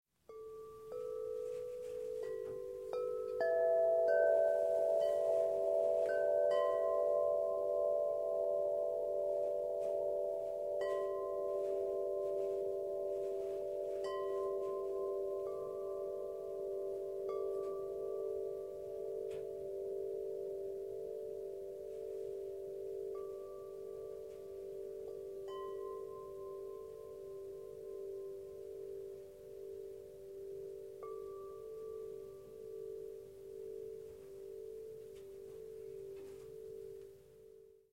Carillons accordés à la main.
L'utilisation de tubes à broche centrale donne à chaque carillon une résonance, un volume et une tonalité cristallins.
Un matériau de percussion souple donne à chaque carillon une réverbération douce et apaisante.